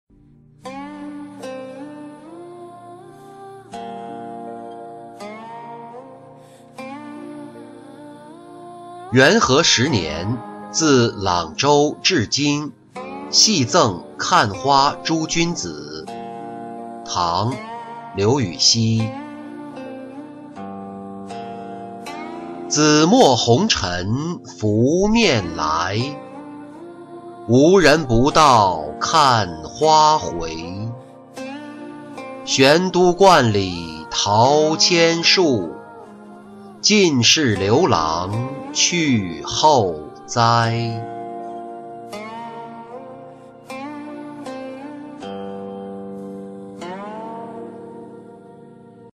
元和十年自朗州至京戏赠看花诸君子-音频朗读